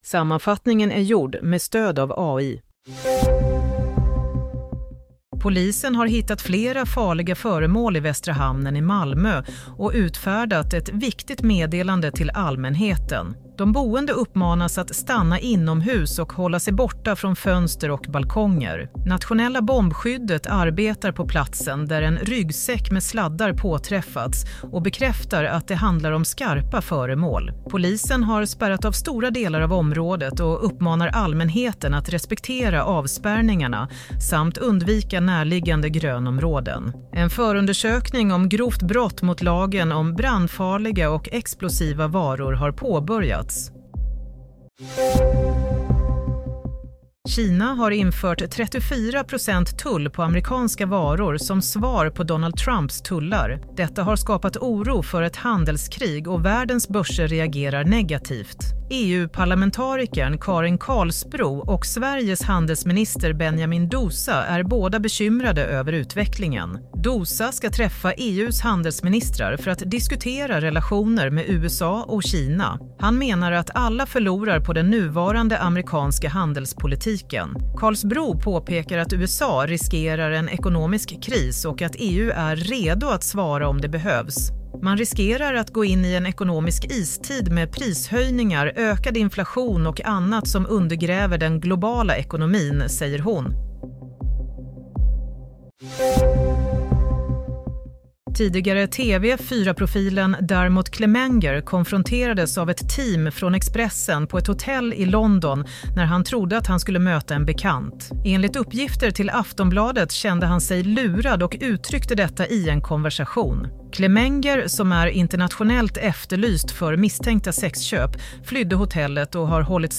Nyhetssammanfattning – 4 april 22:00
Sammanfattningen av följande nyheter är gjord med stöd av AI.